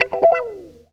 GTR 39 EM.wav